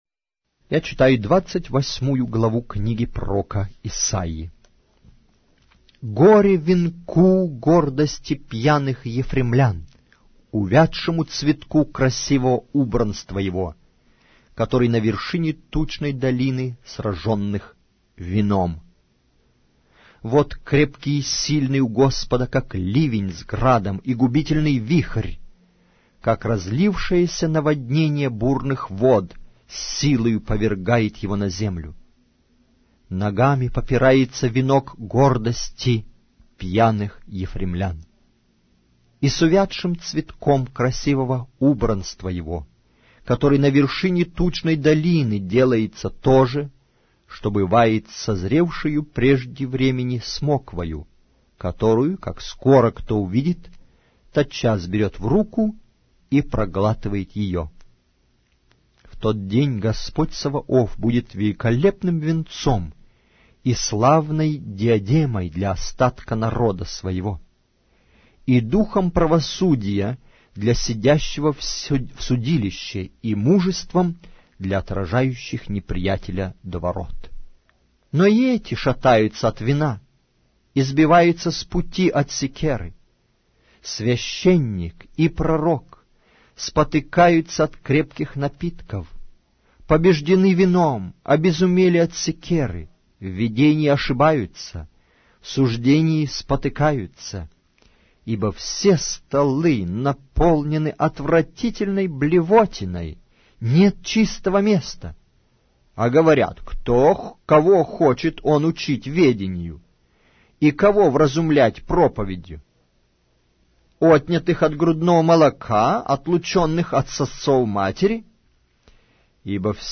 Аудиокнига: Пророк Исаия